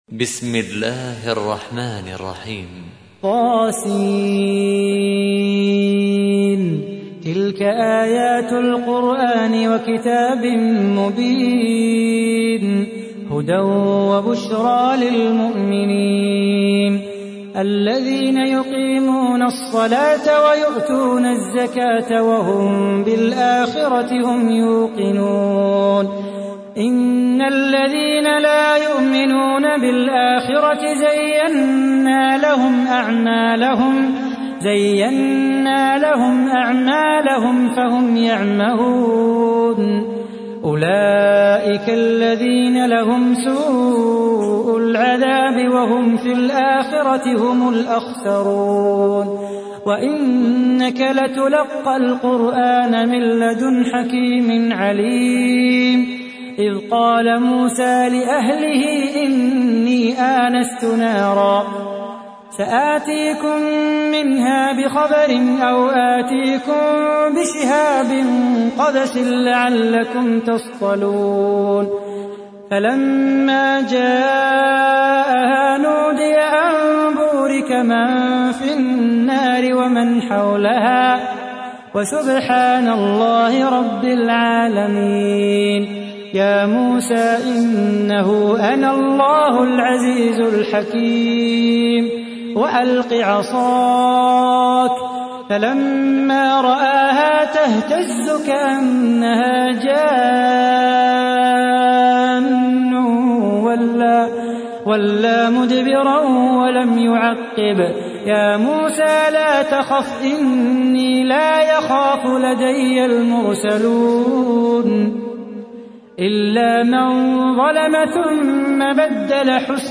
تحميل : 27. سورة النمل / القارئ صلاح بو خاطر / القرآن الكريم / موقع يا حسين